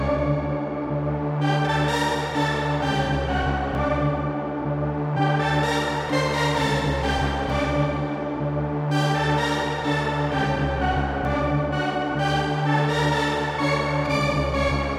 电子琴弦
标签： 128 bpm Electro Loops Strings Loops 2.52 MB wav Key : D
声道立体声